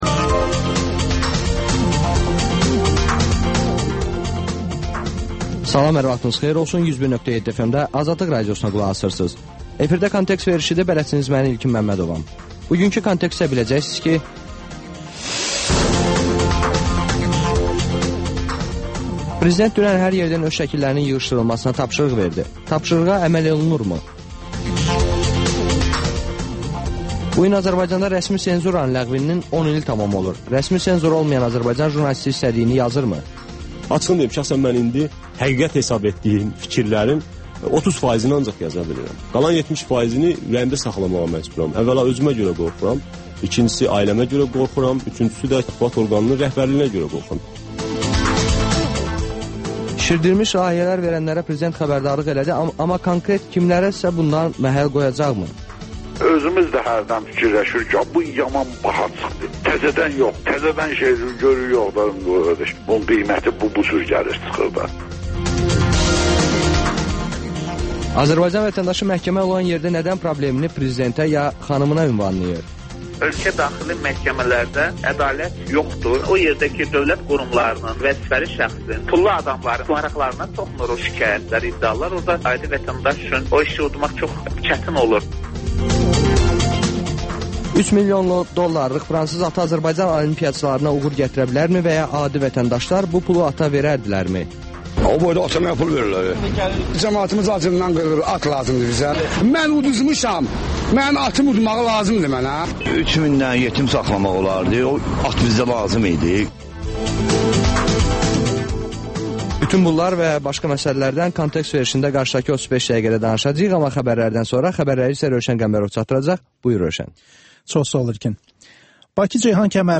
Xəbərlər, müsahibələr, hadisələrin müzakirəsi, təhlillər, daha sonra 14-24: Gənclər üçün xüsusi veriliş